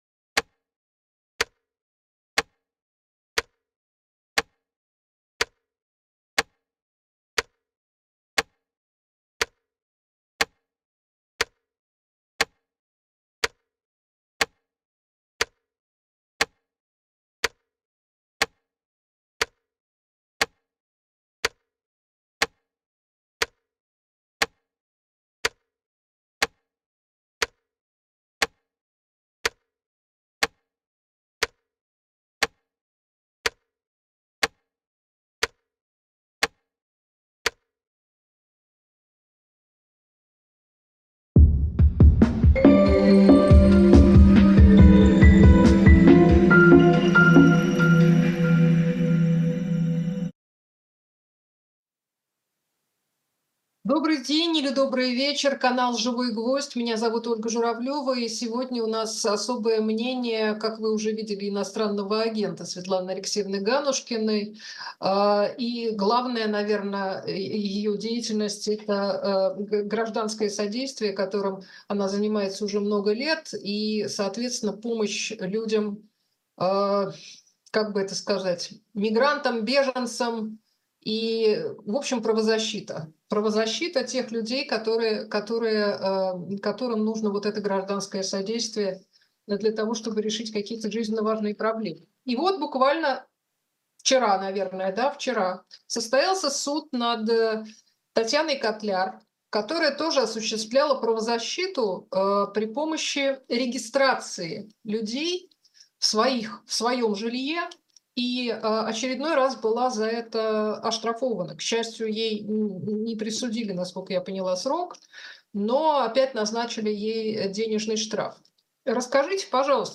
Эфир ведёт Ольга Журавлёва